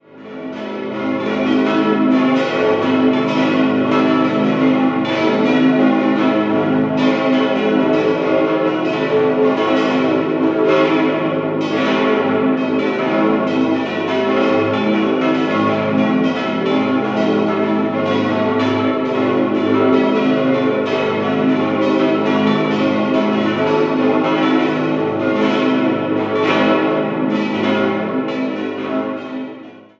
Die Pfarrkirche St. Ulrich auf dem Ulrichsberg wurde in den Jahren 1675/76 nach den Plänen von Pater Maurus Heidelberger OSB errichtet. 6-stimmiges Geläut: a°-cis'-e'-fis'-a'-h' Die vier mittleren Glocken wurden 1907 von der Gießerei Rüetschi in Aarau gegossen.